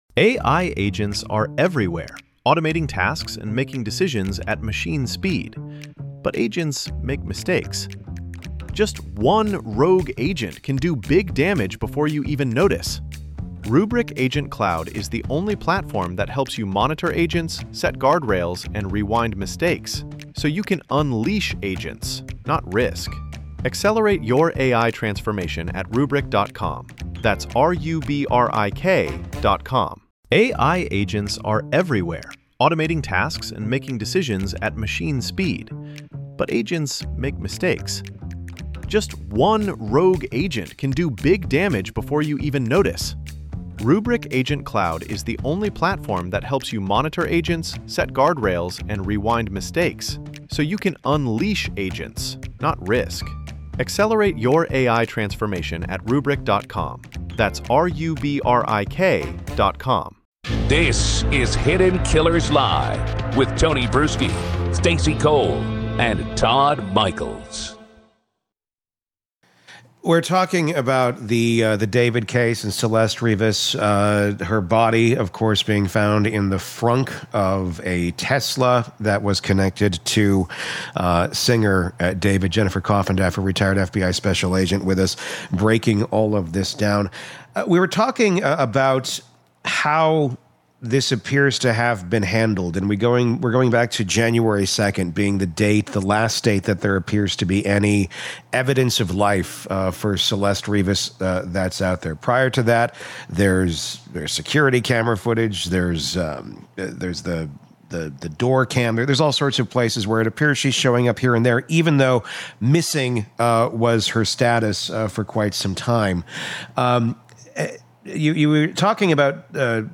Did LAPD Miss Evidence in the D4vd Investigation? A Former FBI Agent Reacts